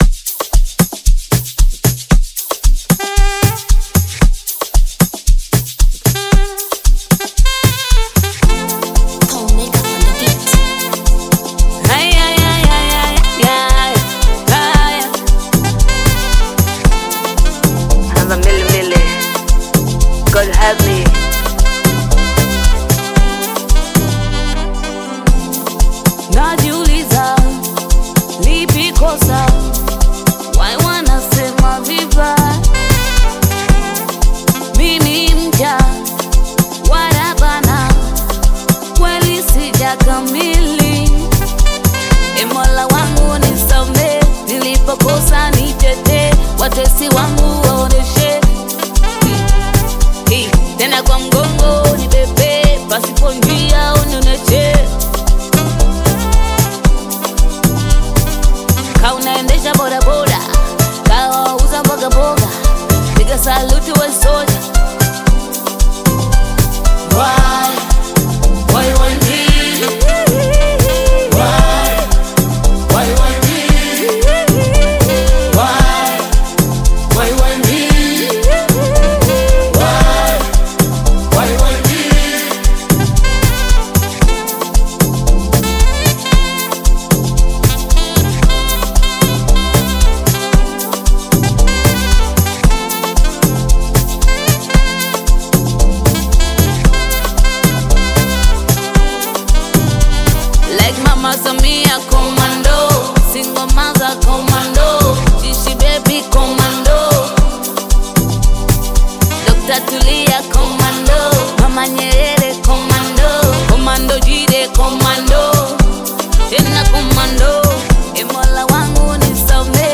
Tanzanian Bongo Flava artist, singer, and songwriter